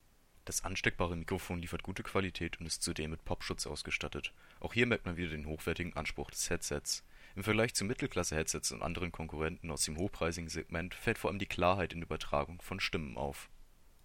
Das ansteckbare Mikrofon liefert gute Qualität und ist zudem mit Popschutz ausgestattet.
Im Vergleich zu Mittelklasse-Headsets und anderen Konkurrenten aus dem hochpreisigen Segment fällt vor allem die Klarheit in der Übertragung von Stimmen auf.
Teufel-Cage-Mikrofon.mp3